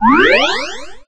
Heal3.ogg